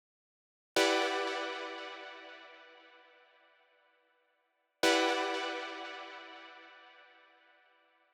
12 Synth PT4.wav